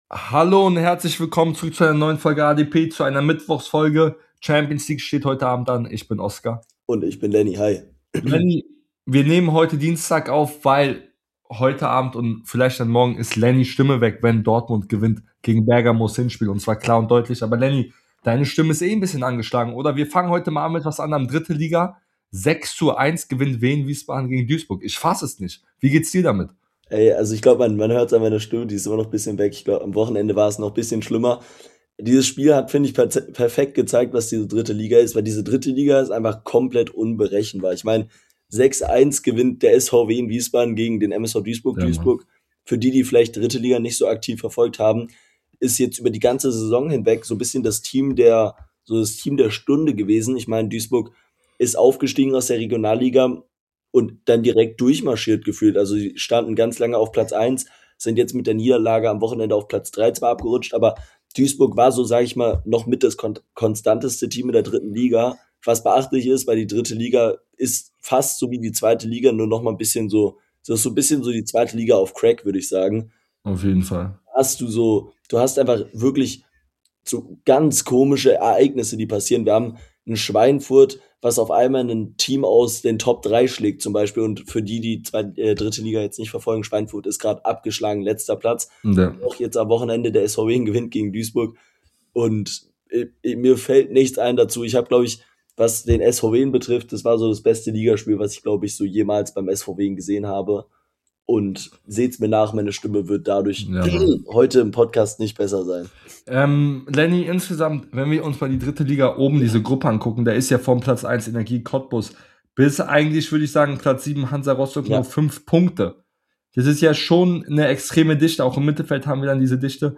In der heutigen Folge sprechen die beiden Hosts über den 3 Liga Aufstiegskampf , den Bundesliga Abstiegskampf und den 2 Liga Aufstiegskampf , alles eng beieinander